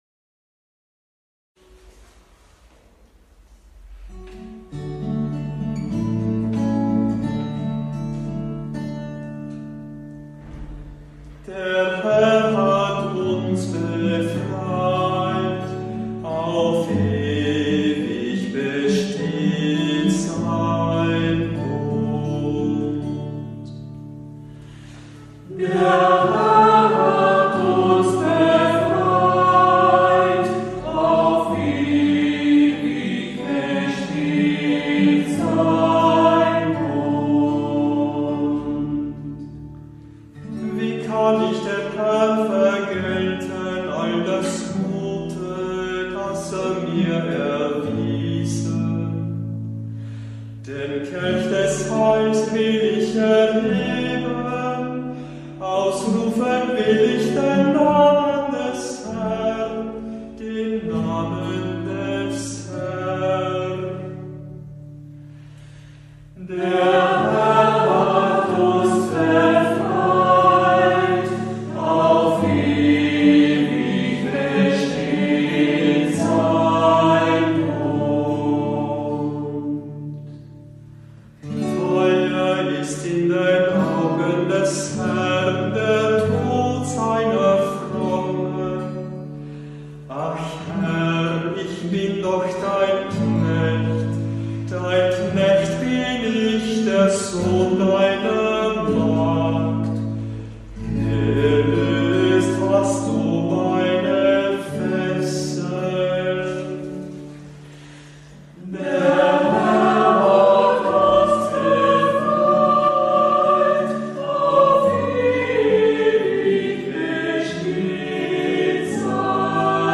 Hörbeispiele aus verschiedenen Kantorenbüchern
Psalmen aus dem Gurker Psalter für Kantor mit Orgel- oder Gitarrenbegleitung für die Osternacht finden Sie hier.